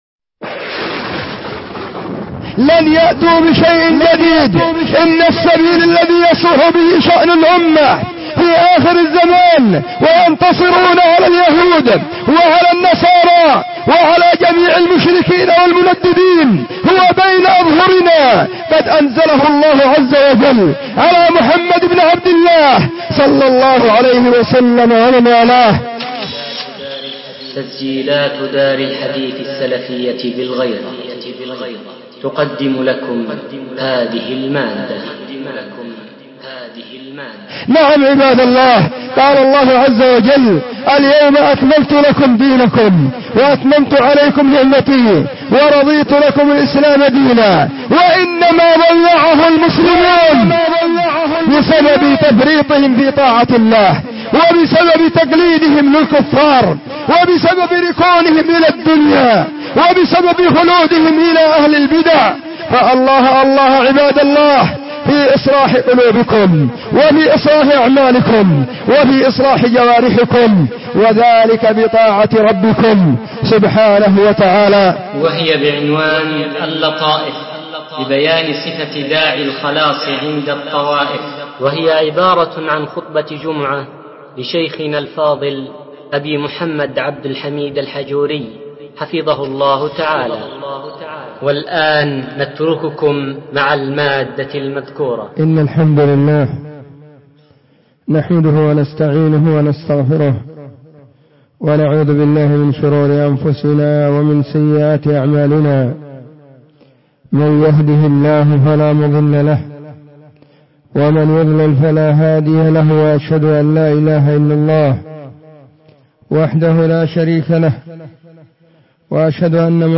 خطبة جمعة بعنوان :اللطائف ببيان صفة داعي الخلاص عند الطوائف
📢 وكانت – في – مسجد – الصحابة – بالغيضة – محافظة – المهرة – اليمن.